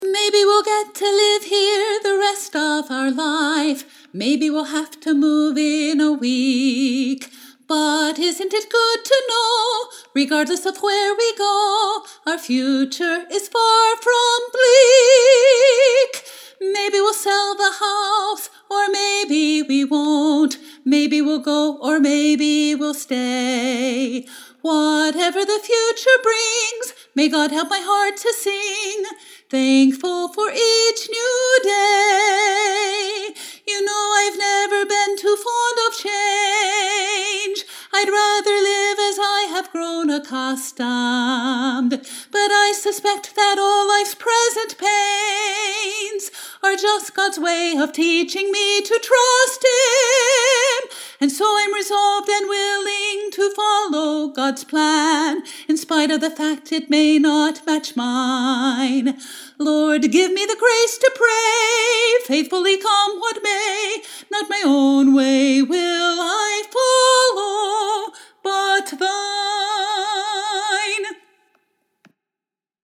You can listen to me singing my new lyrics below.